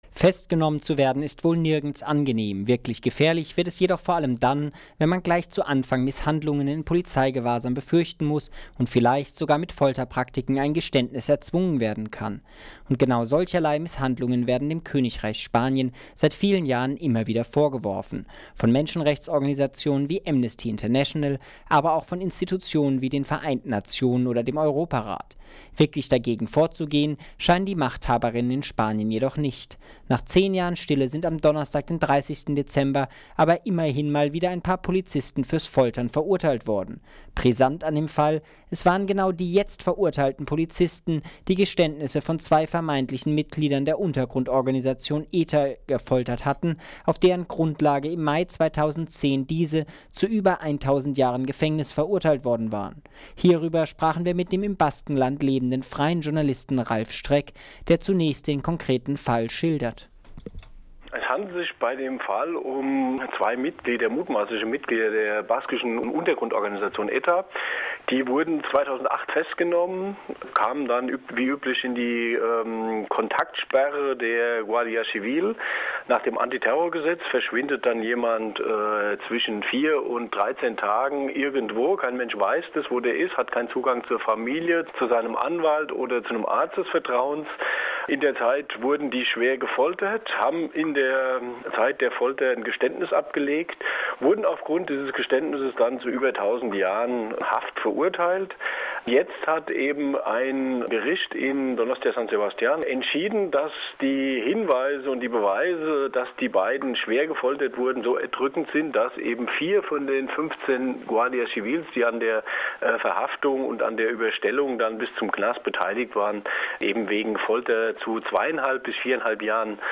Folterer erstmals seit 10 Jahren in Spanien wieder verurteilt - Interview